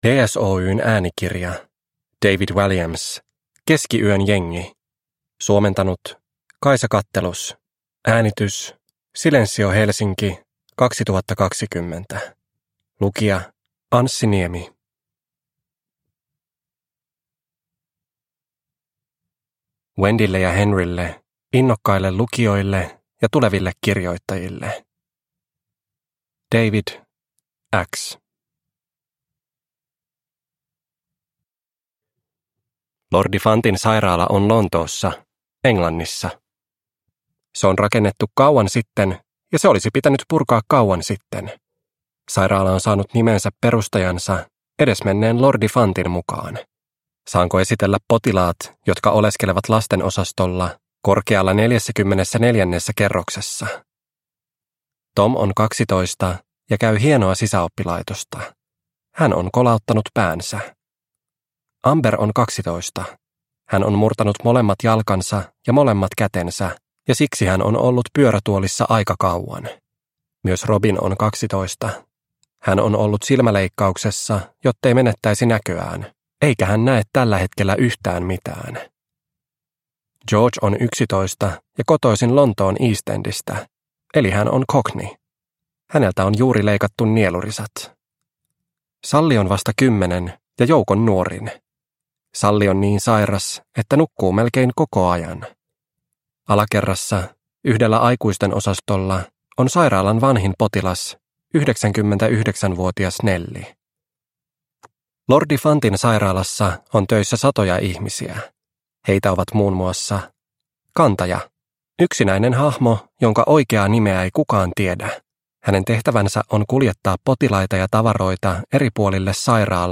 Keskiyön jengi – Ljudbok – Laddas ner